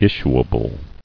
[is·su·a·ble]